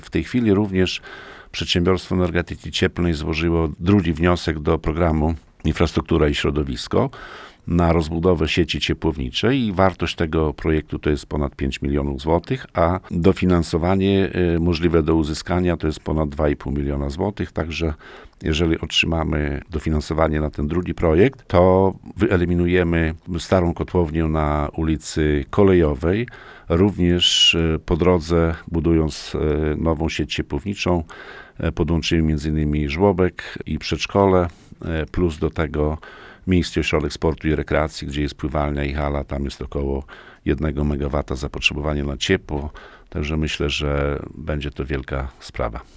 – By to osiągnąć PEC stara się również o dofinansowanie innego projektu – wyjaśnia Wacław Olszewski, burmistrz Olecka.